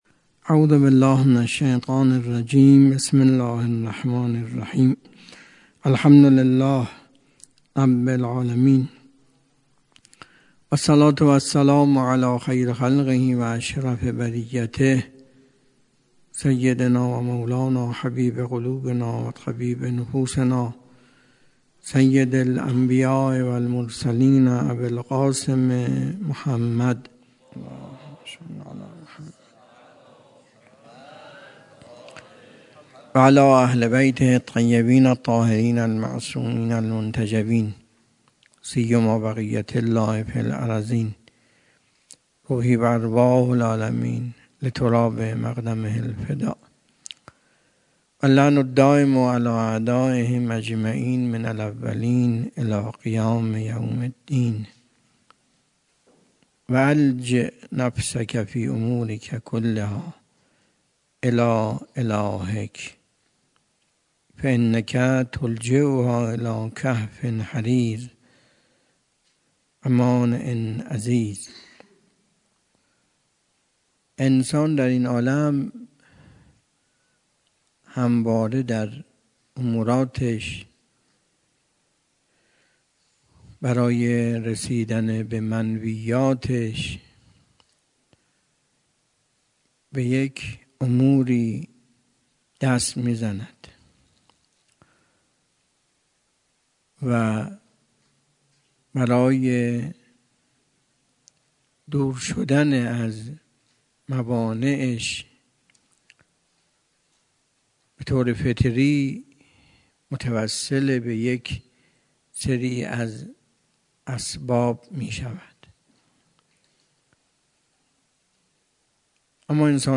درس اخلاق حوزه علمیه مروی سخنرانی